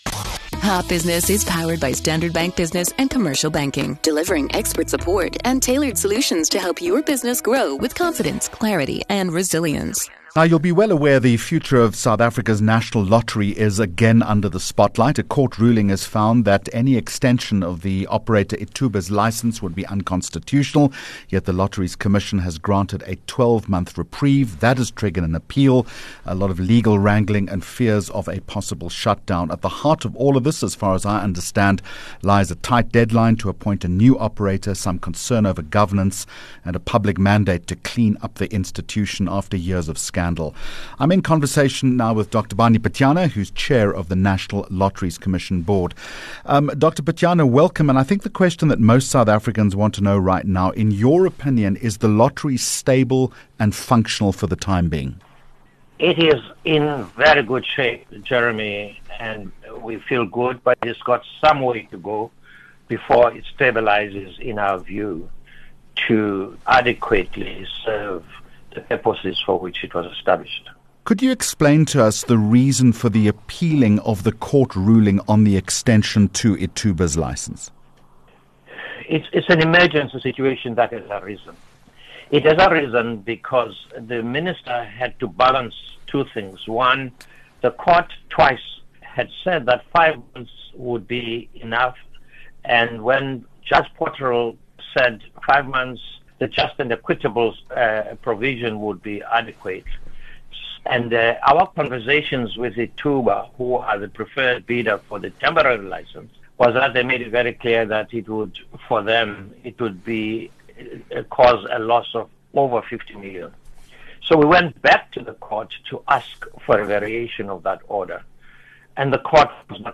5 Jun Hot Business Interview - Prof Barney Pityana 05 June 2025
BUSINESS INSIGHTS Topic: What needs to change for a more effective Lottery commission Guest: Prof Barney Pityana: Chairman, National Lotteries Commission